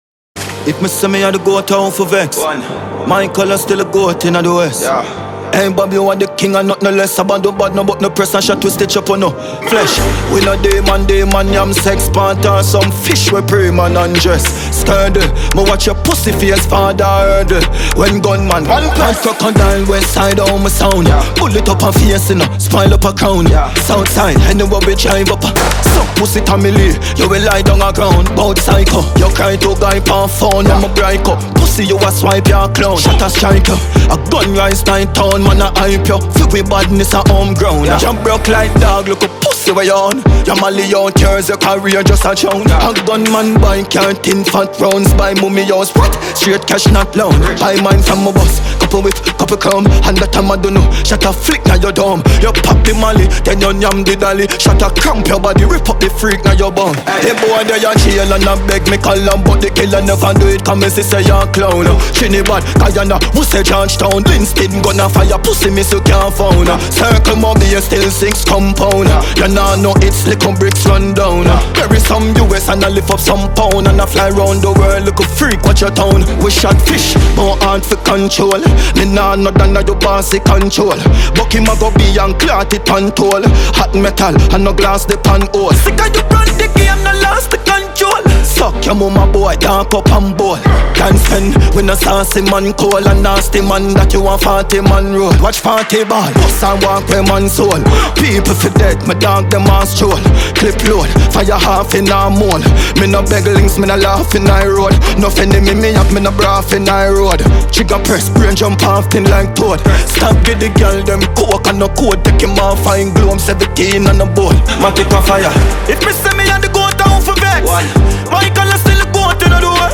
Dancehall
diss track